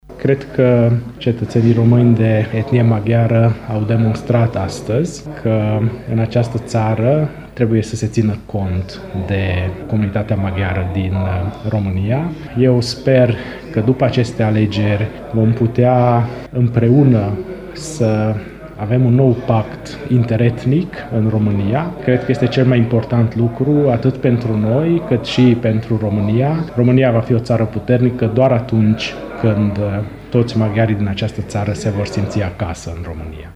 Preşedintele UDMR Sfântu Gheorghe, Antal Arpad, care este şi primarul municipiului, a declarat că la acest scor comunitatea maghiară este o forţă puternică, ce trebuie luată în considerare: